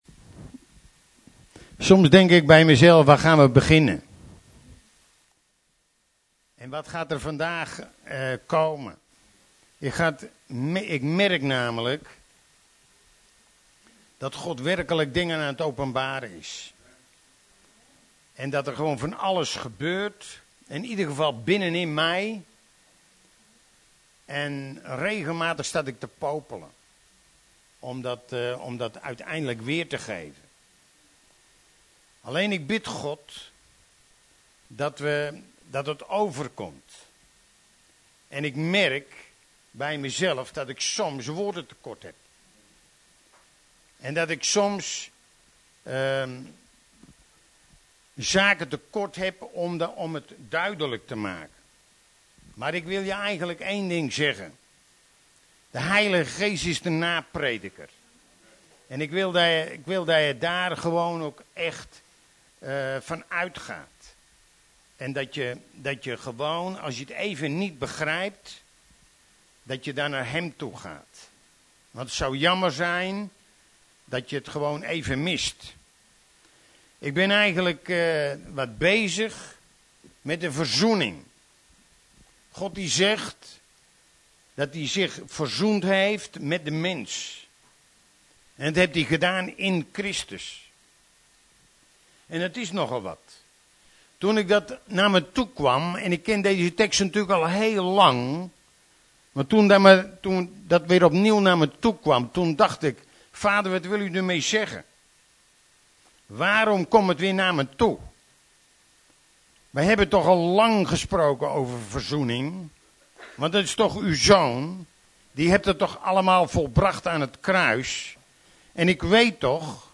Preek